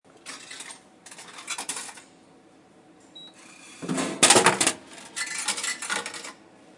Tiếng Máy bán hàng Tự động
Thể loại: Tiếng đồ công nghệ
Description: Tiếng máy bán hàng tự động, âm thanh máy bán nước, tiếng máy tự động phân phối hàng hóa, hoặc tiếng máy vending hoạt động, cơ chế trượt hoặc đẩy hàng ra khỏi khay, kèm theo tiếng đồ vật rơi xuống khay lấy hàng – tất cả được tái hiện một cách rõ nét, chân thực và chuyên nghiệp mô phỏng đầy đủ quá trình máy bán hàng tự động vận hành và nhả sản phẩm.
tieng-may-ban-hang-tu-dong-www_tiengdong_com.mp3